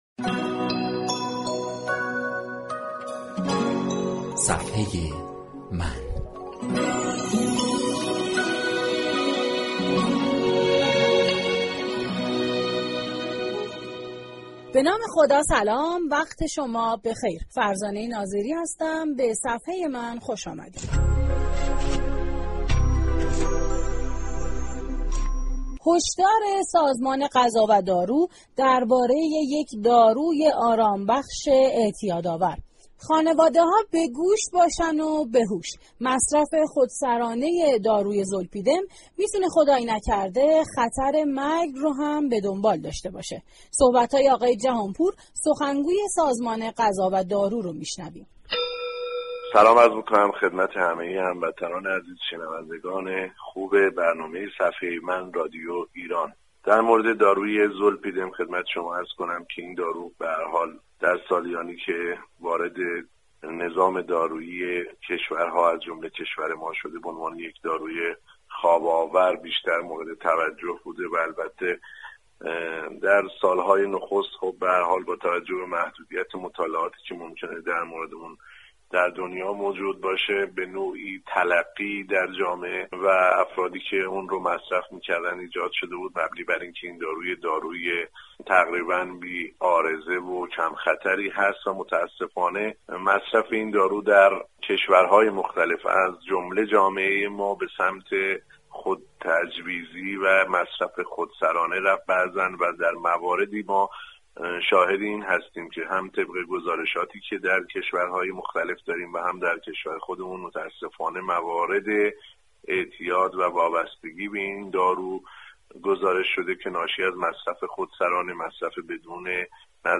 كیانوش جهانپور سخنگوی سازمان غذا و دارو در بخش صفحه من رادیو ایران